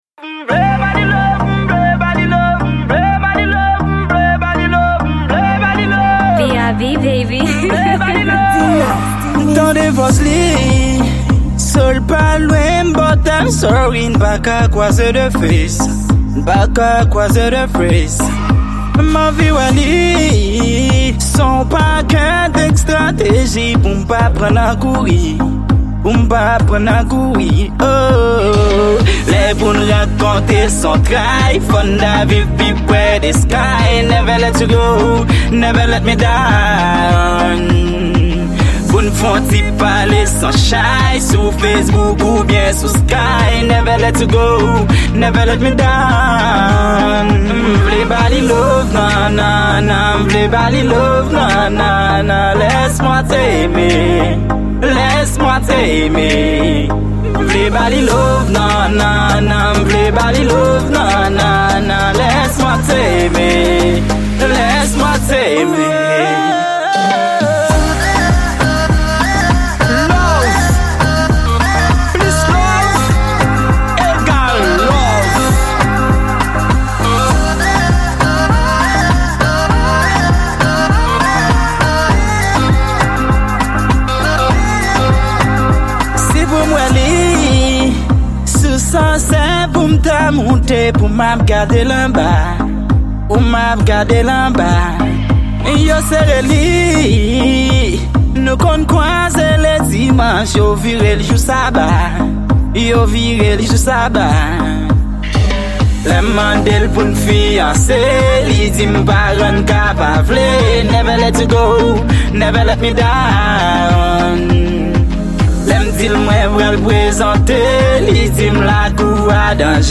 Genre: House.